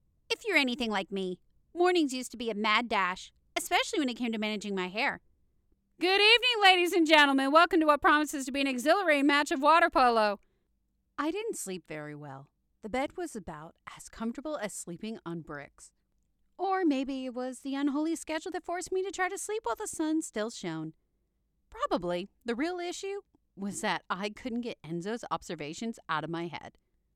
Narrations, Informercial, Sportscaster
British, Germain, Southern, French